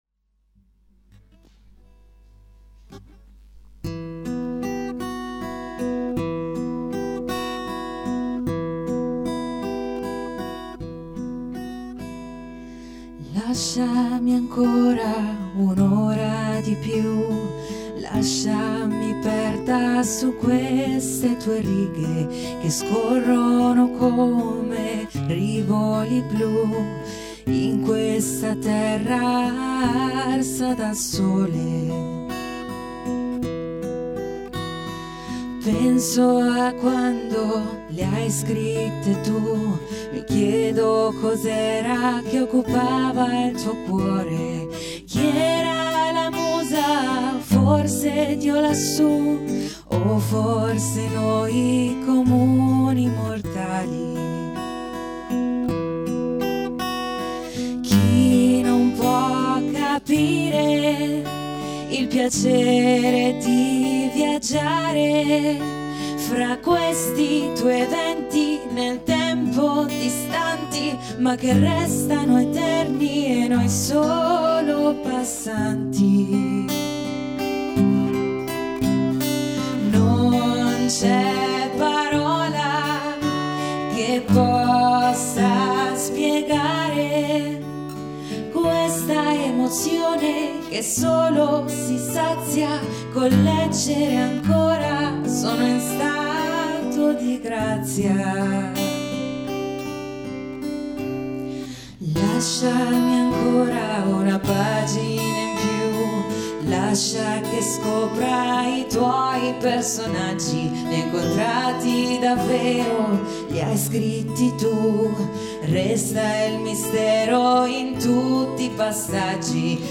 Intervento musicale con la canzone “In stato di Grazia”
voce
chitarra